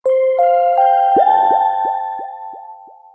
Kategori Meddelande